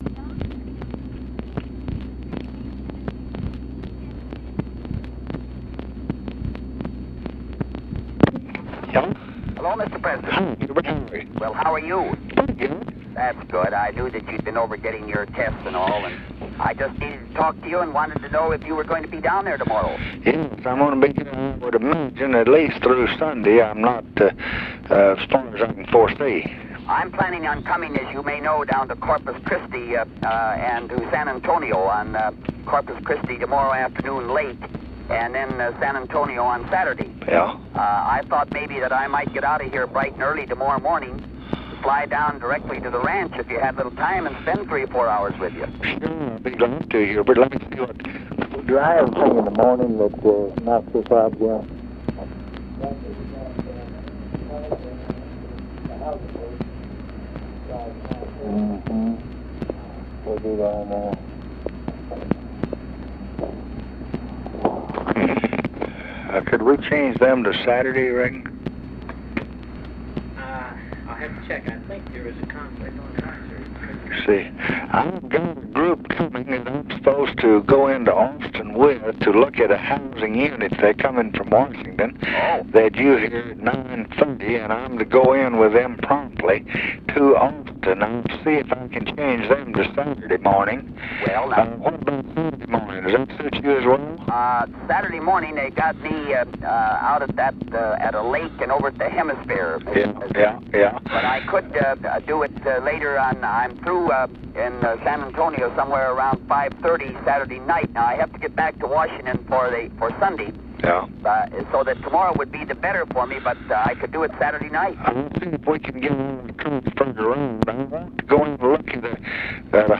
Conversation with HUBERT HUMPHREY and OFFICE CONVERSATION, August 8, 1968
Secret White House Tapes